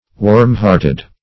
Warm-hearted \Warm"-heart`ed\, a.